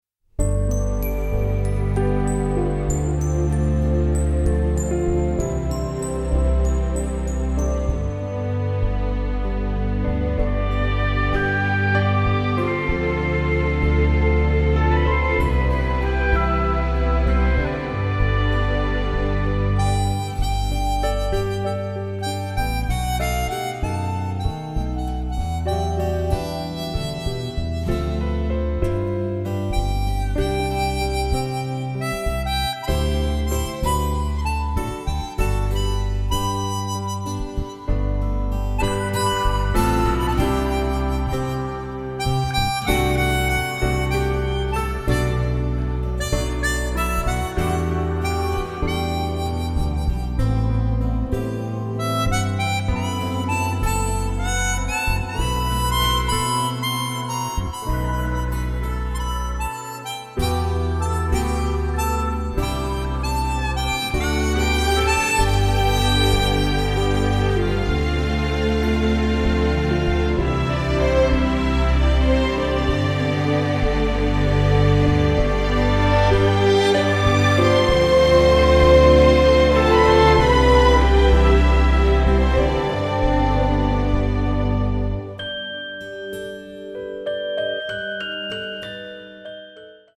This orchestral album
A nice easy listening version of music